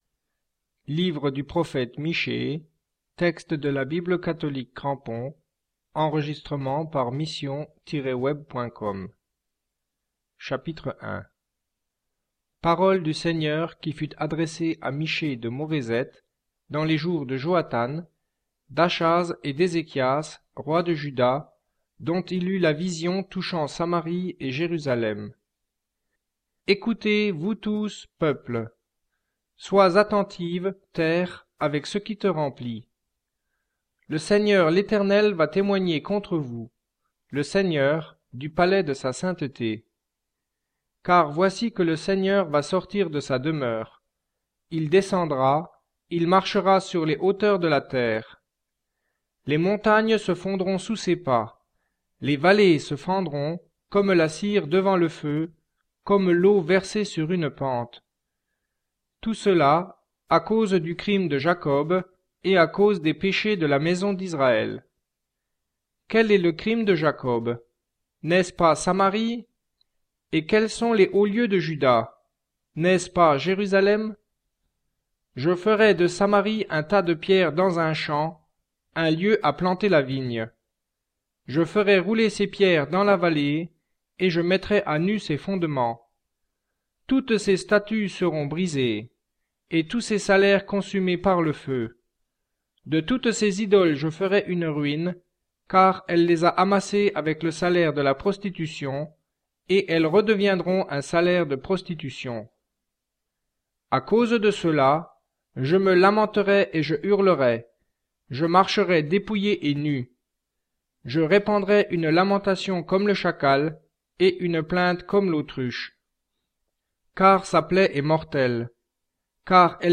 Audio Bible
Il convient donc de les remplacer à la lecture par "le Seigneur" voir 'l'Éternel".
Cette précision apportée, le texte lu est aussi fidèle que possible à l'écrit.